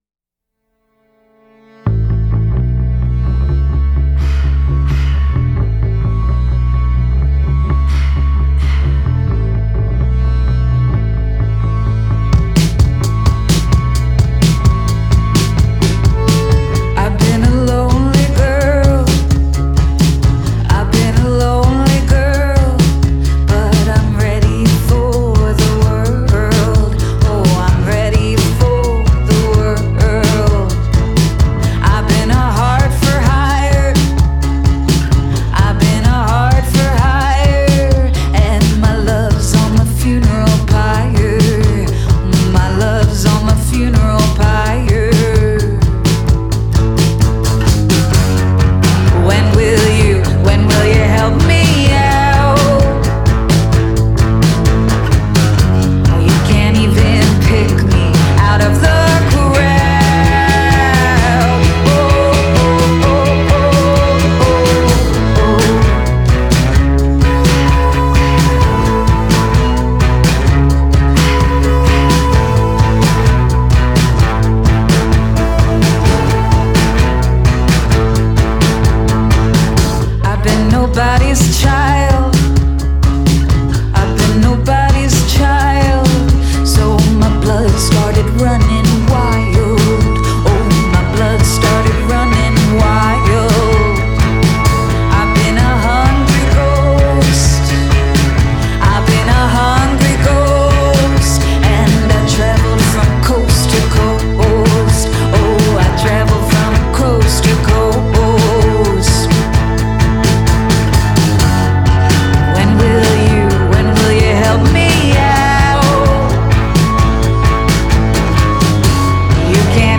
ardent folk and harmonious indie rock